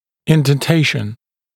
[ˌɪnden’teɪʃn][ˌиндэн’тэйшн]вдавленность, впадина, отпечаток (напр. отпечатки зубов на боковых поверхностях языка)